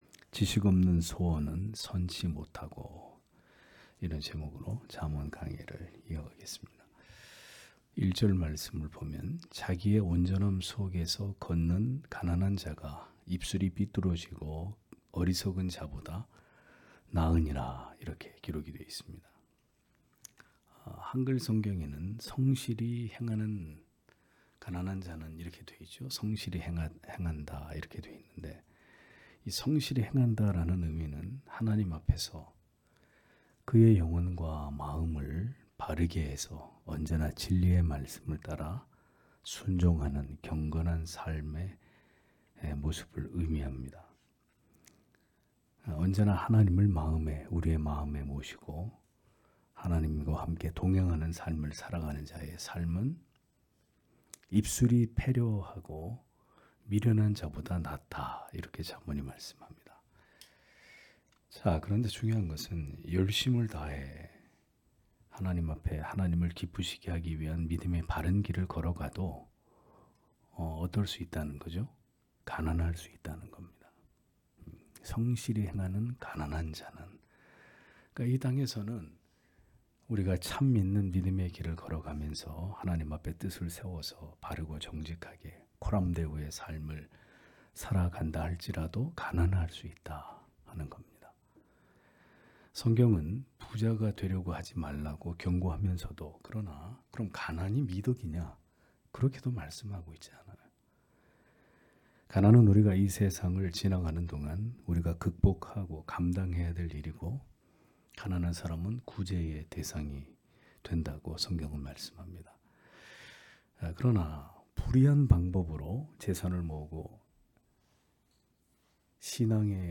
수요기도회 - [잠언 강해 111] 지식없는 소원은 선치 못하고(잠 19장 1-4절)